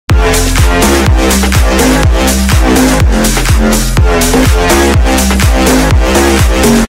ali-a-intro-normal-made-with-Voicemod-technology.mp3